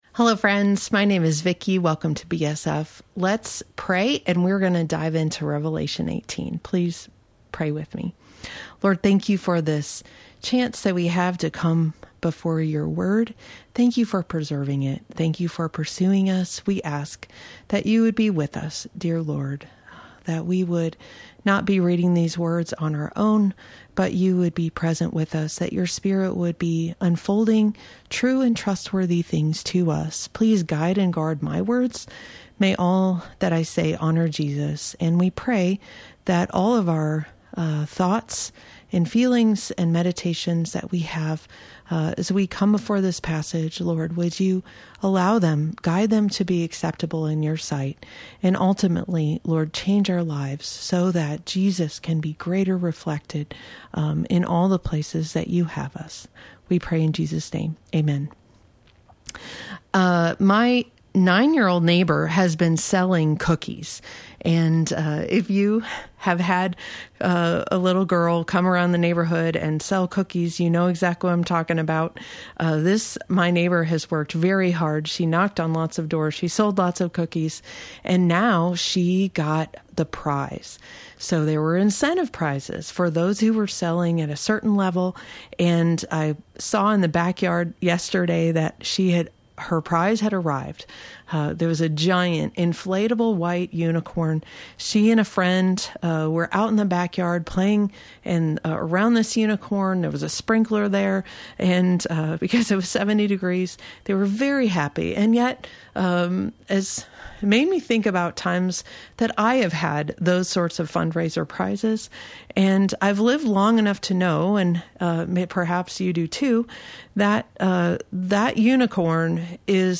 Weekly Bible Teaching